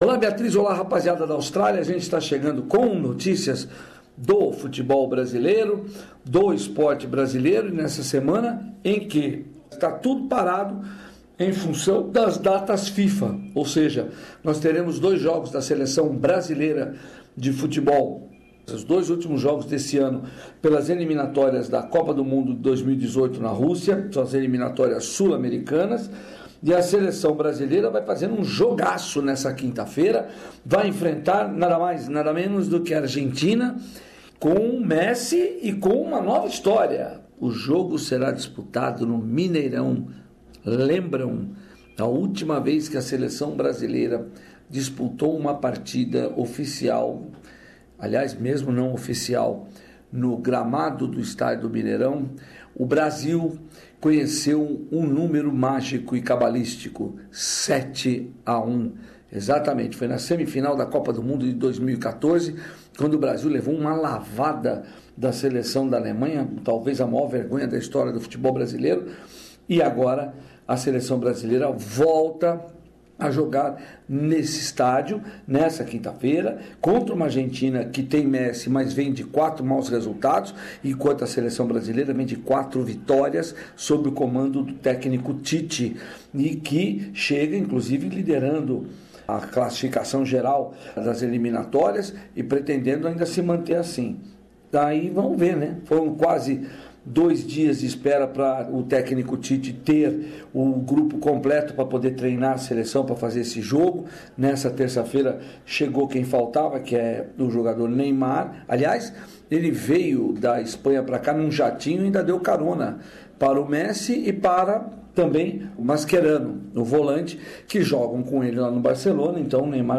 Boletim semanal